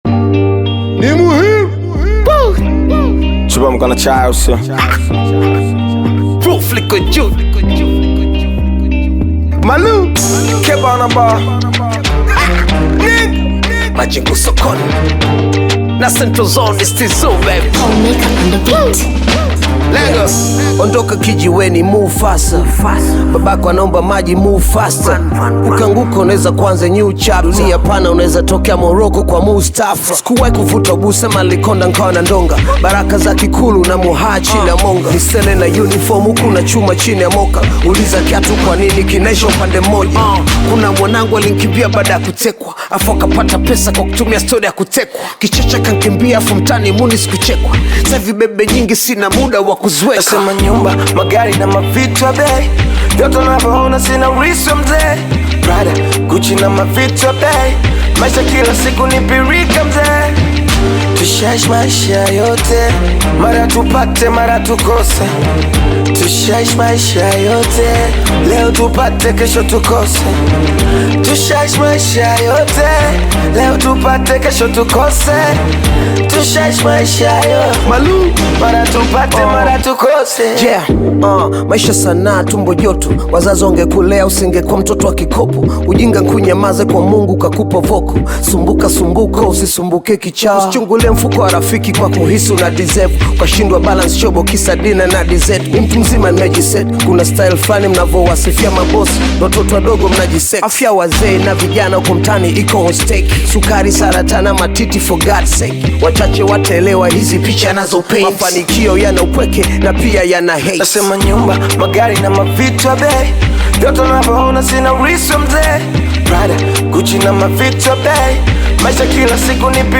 is a dynamic Hip-Hop single